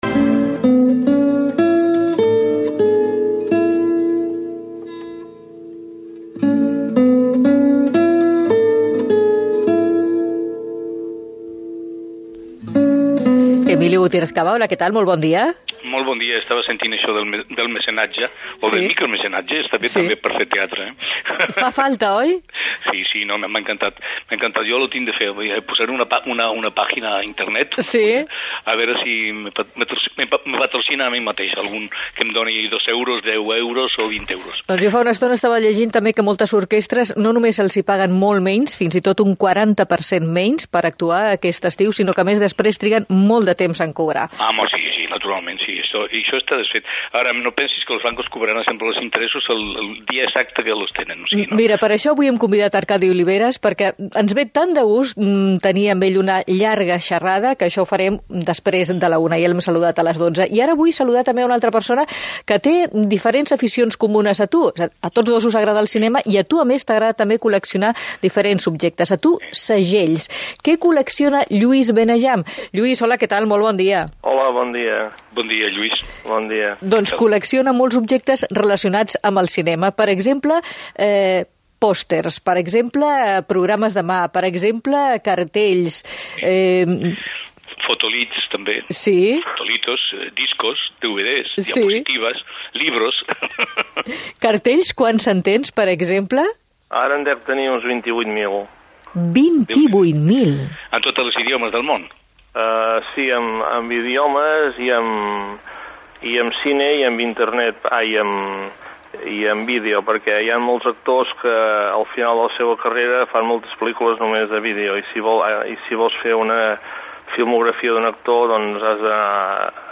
Pude hablar con Emilio Gutiérrez Caba colaborador habitual en este programa para comunicarle los actos que estábamos preparando y al mismo tiempo invitarle a participar.